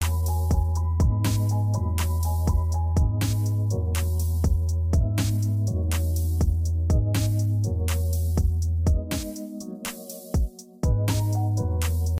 این ریمیکس ،با افکت‌ها و بیس‌های آماده این ابزار ساخته شده.